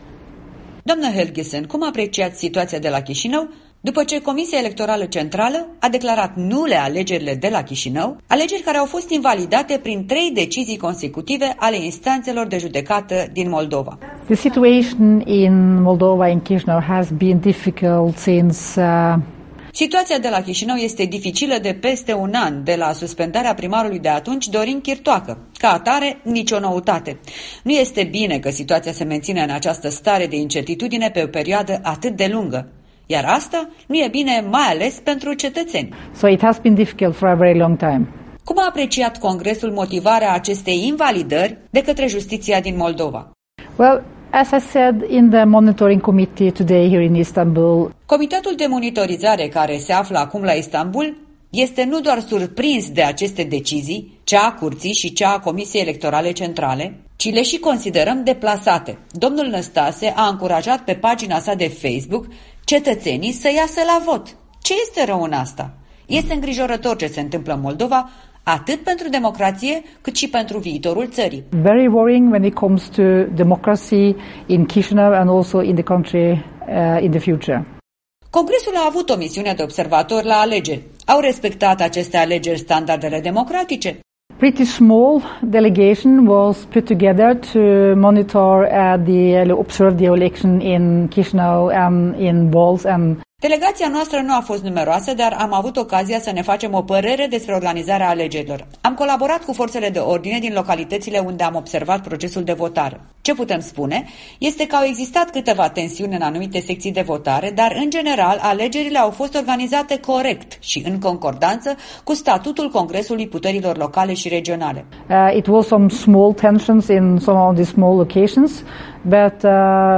Interviu cu raportoarea pentru Moldova din Comitetul de Monitorizare al Congresului Puterilor Locale și Regionale de pe lângă Consiliul Europei.